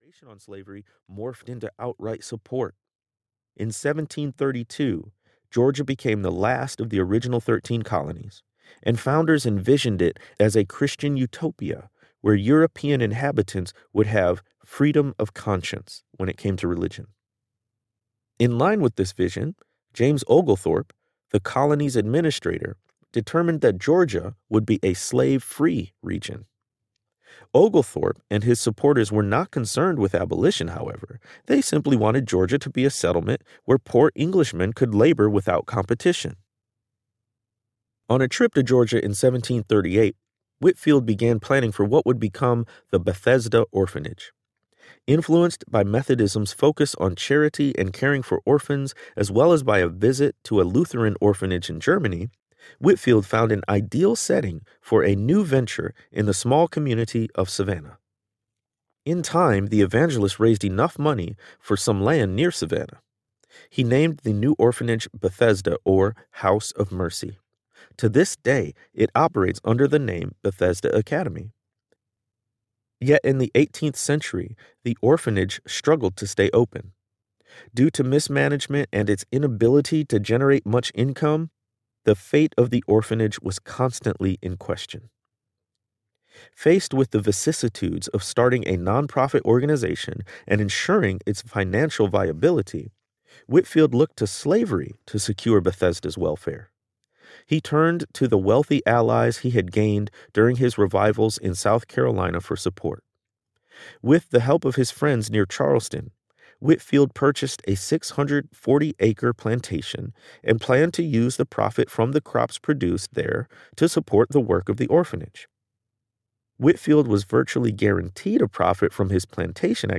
The Color of Compromise Audiobook
– Unabridged